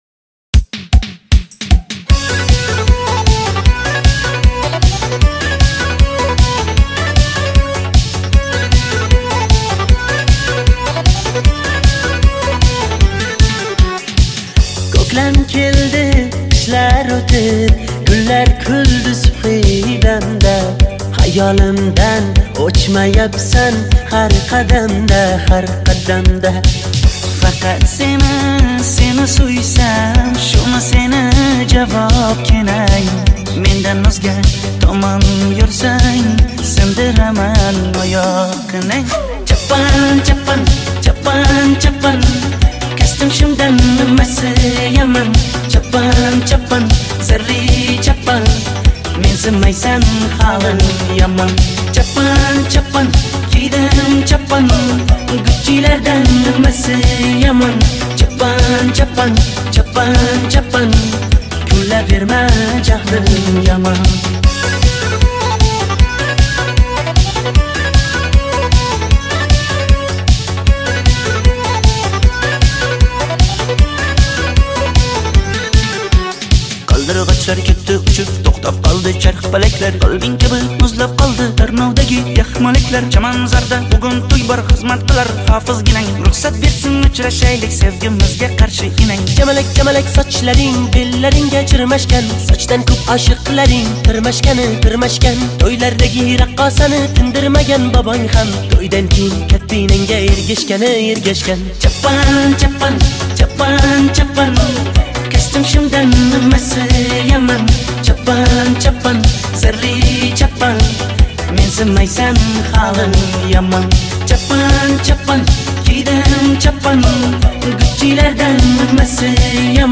Жанр: Узбекские песни Слушали